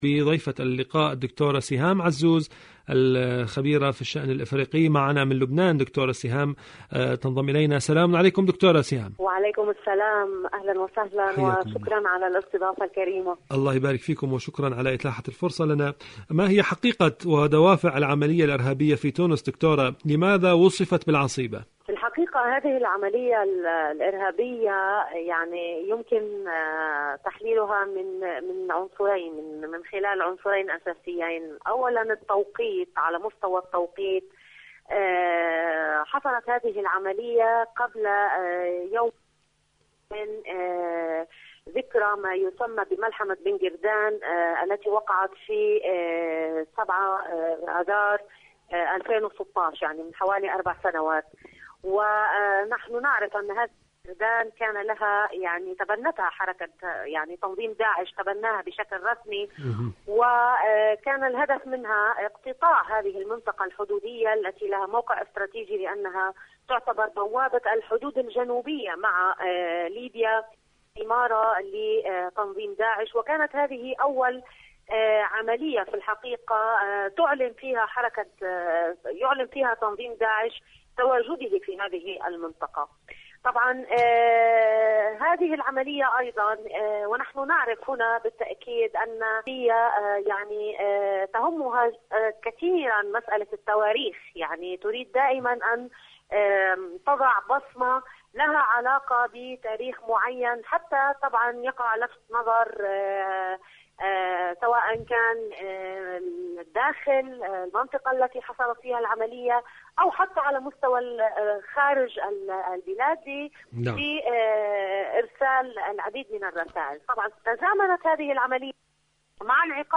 مقابلات برامج إذاعة طهران العربية برنامج صدى المغرب العربي مقابلات إذاعية تونس هجوم تونس الانتحاري الهجوم على السفارة الأمريكية في تونس سفارة أمريكا في تونس شاركوا هذا الخبر مع أصدقائكم ذات صلة أحبك يا حماتي..